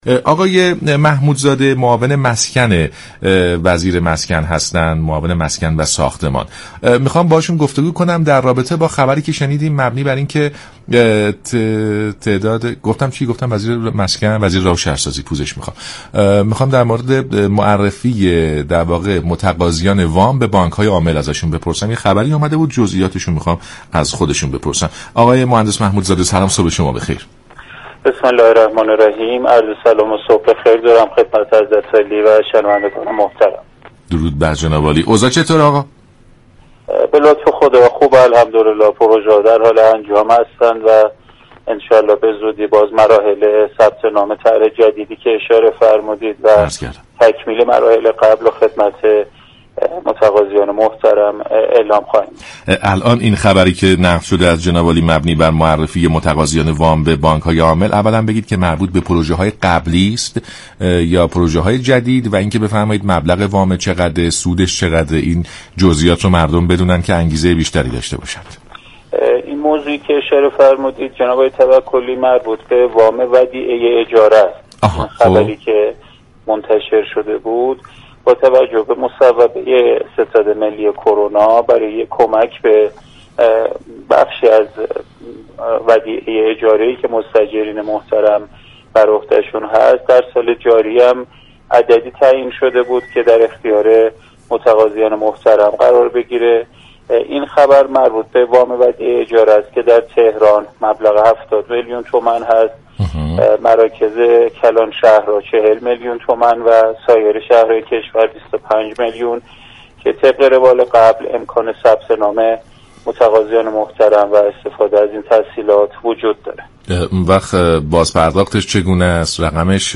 به گزارش شبكه رادیویی ایران، محمود محمودزاده معاون مسكن و ساختمان وزارت راه و شهرسازی در برنامه «سلام صبح بخیر» درباره جزئیات معرفی متقاضیان وام ودیعه اجاره مسكن به بانك های عامل گفت: وام ودیعه اجاره مسكن طبق مصوبه ستاد ملی كرونا در راستای كمك به بخشی از ودیعه اجاره مستاجران انجام شده است.